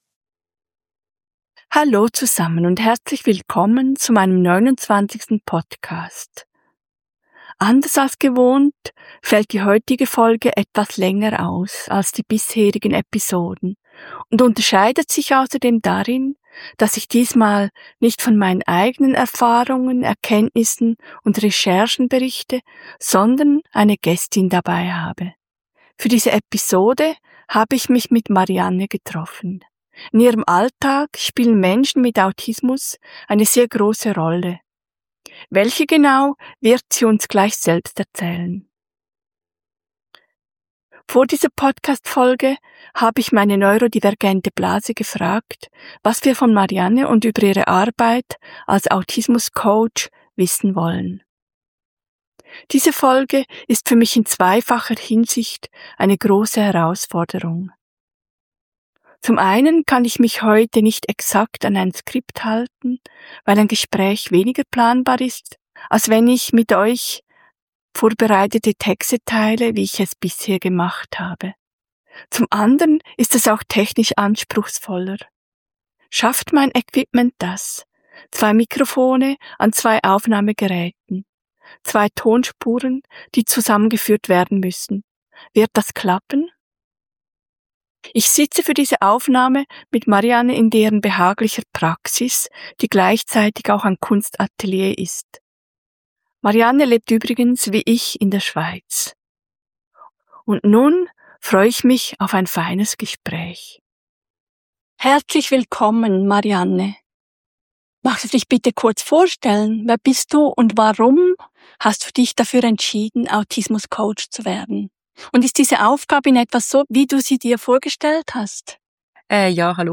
#29 Interview zu Autismus-Coaching ~ Im Spektrum Podcast